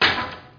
dropwood1.mp3